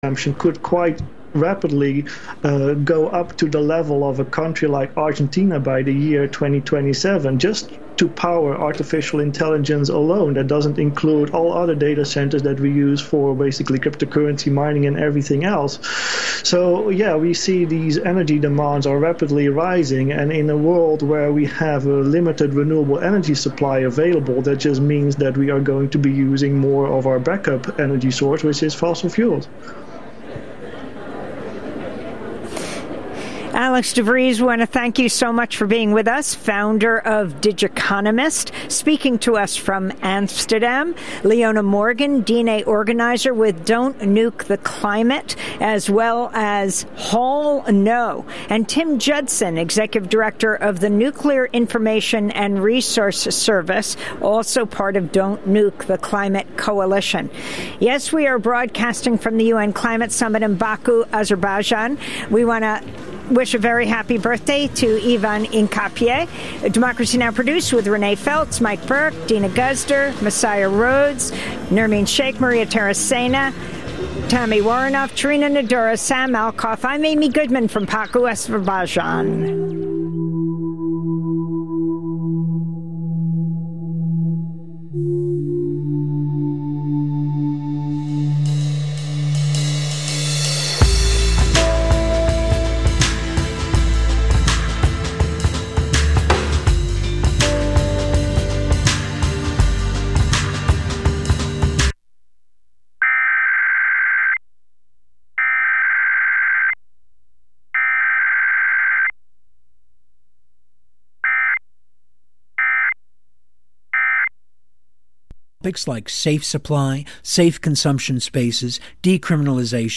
Evening News on 11/19/24